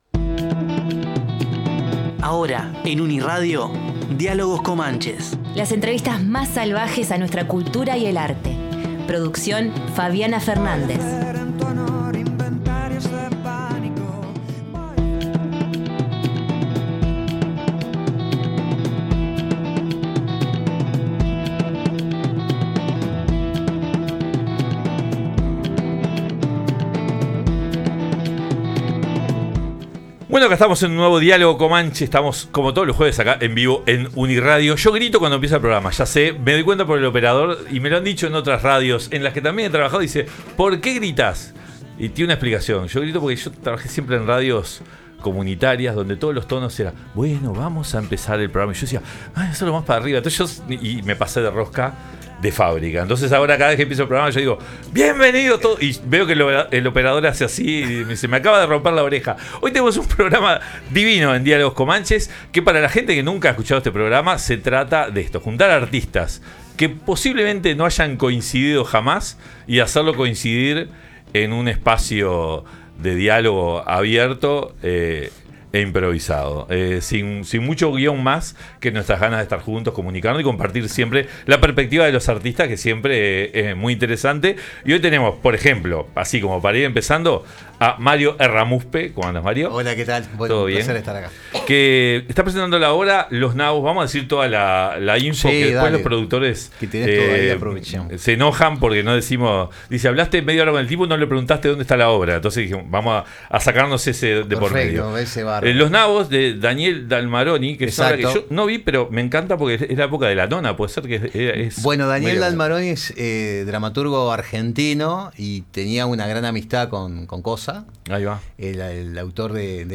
En este ocasión recibimos a Inés Estévez
Actriz de cine, teatro, televisión, cantante de jazz y blues